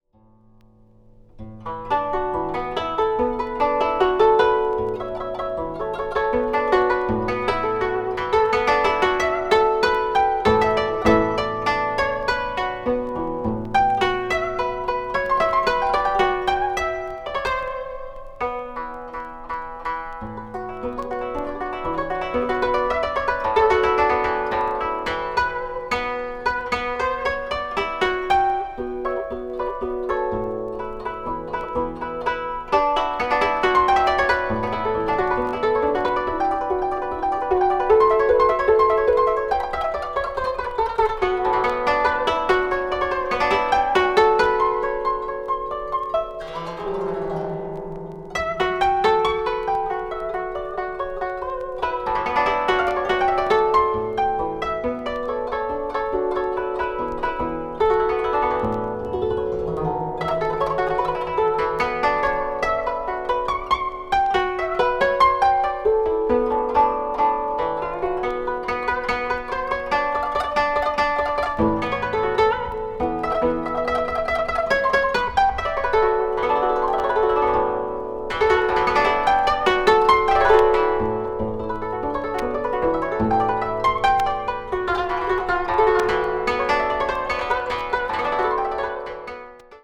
緩・急・緩の3つのパートで構成されながら叙情性豊かな旋律が奏でられています。